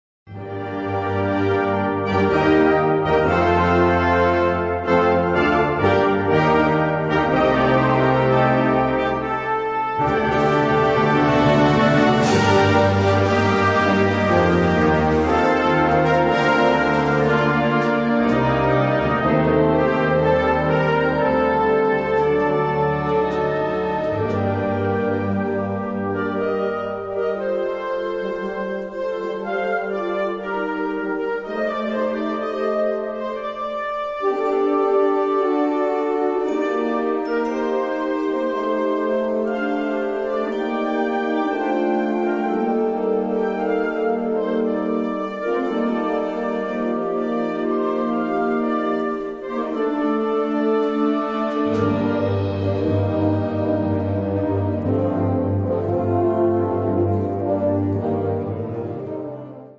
Categorie Harmonie/Fanfare/Brass-orkest
Subcategorie Rhapsody
Bezetting Ha (harmonieorkest)